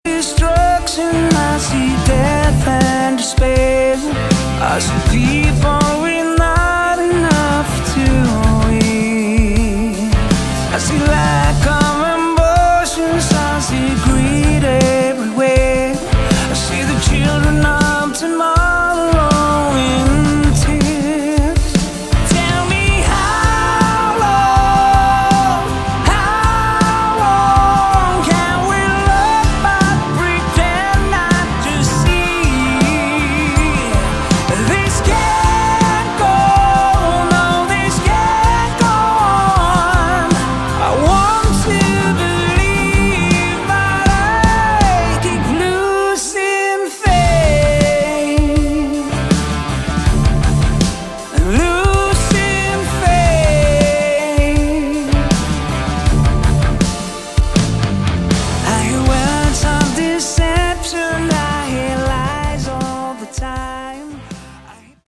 Category: AOR / Melodic Rock
Lead Vocals, Backing Vocals, Keyboards